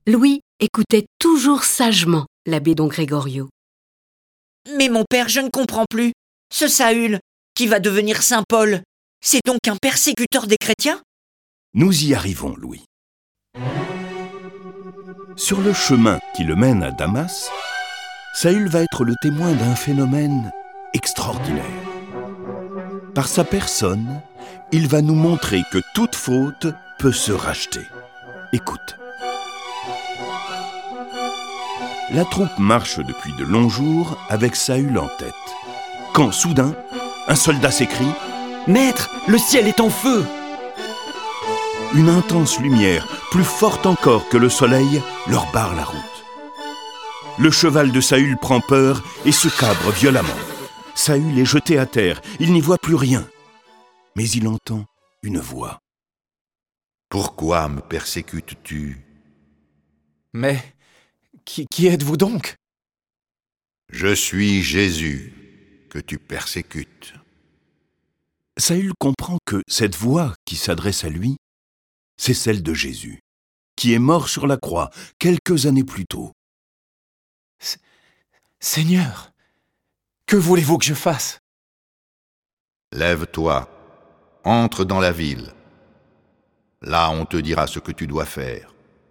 Diffusion distribution ebook et livre audio - Catalogue livres numériques
Cette version sonore de la vie de saint Paul est animée par dix voix et accompagnée de plus de trente morceaux de musique classique.